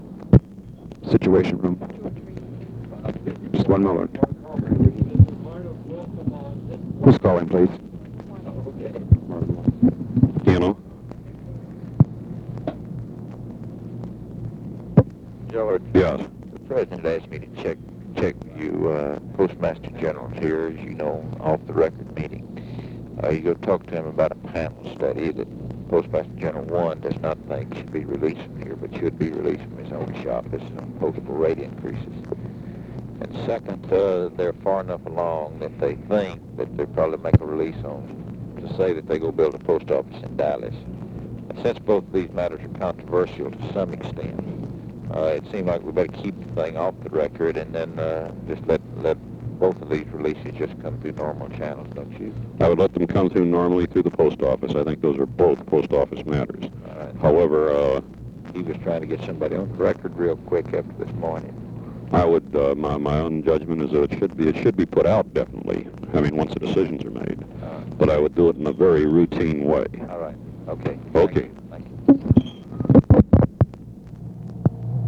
Conversation with MARVIN WATSON and GEORGE REEDY
Secret White House Tapes